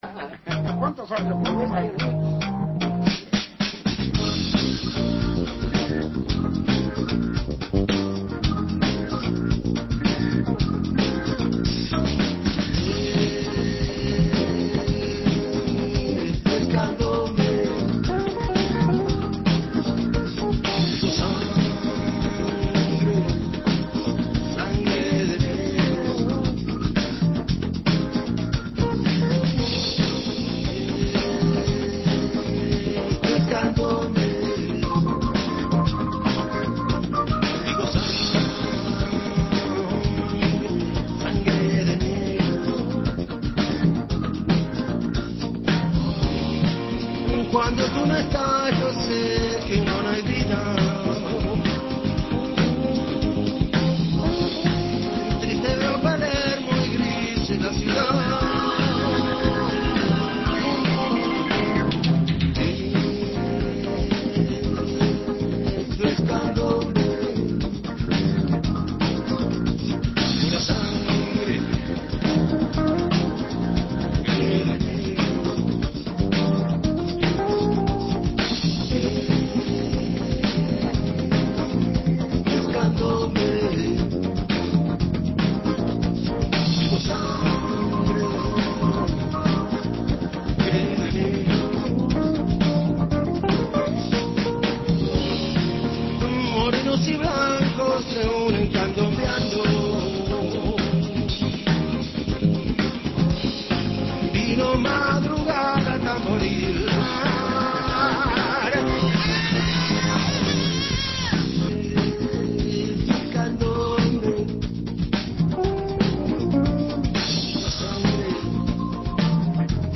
En medio de afinadas y algún que otro café, Beto Satragni visitó Café Torrado para hablar de su partida de Uruguay y su vuelta a los pagos, haciendo un recorrido por su historia musical.